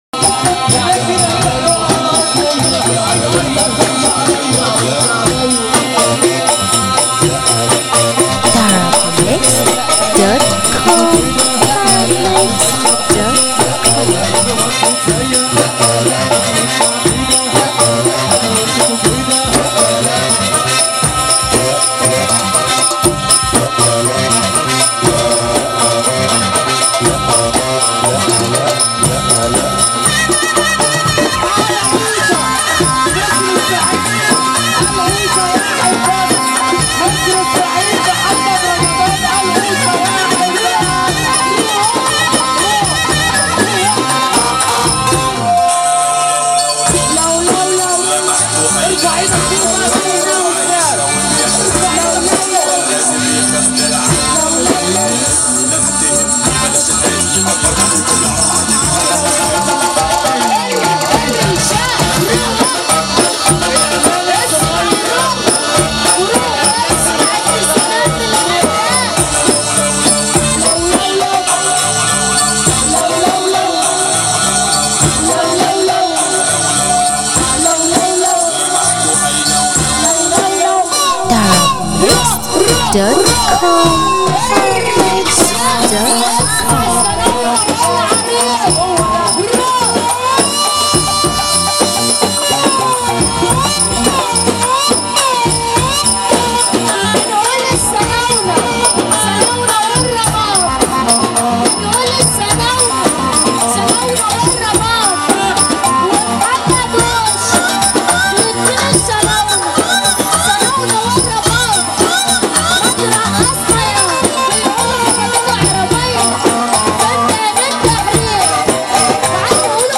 مزمار